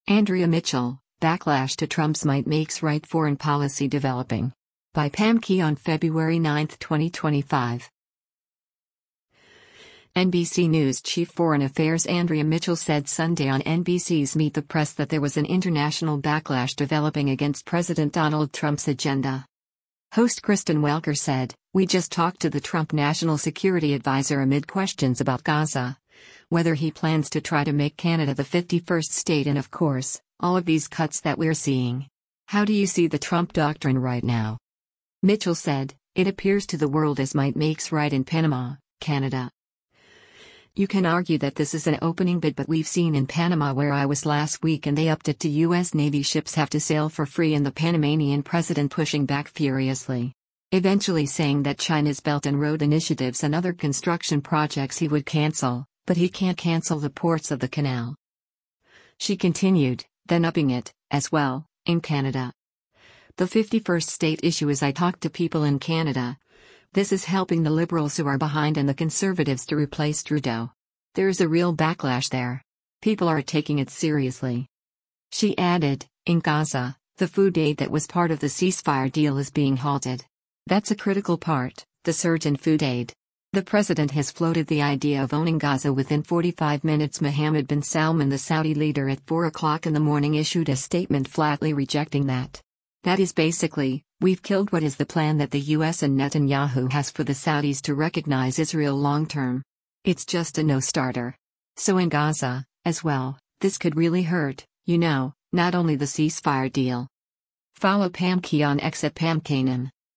NBC News’ chief foreign affairs Andrea Mitchell said Sunday on NBC’s “Meet the Press” that there was an international backlash developing against President Donald Trump’s agenda.